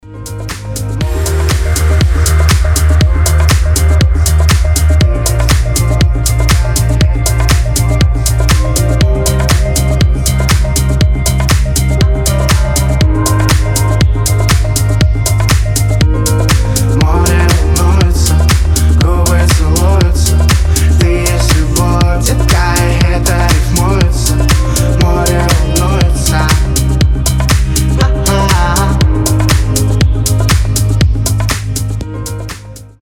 • Качество: 320, Stereo
пианино
приятные
теплые
Chill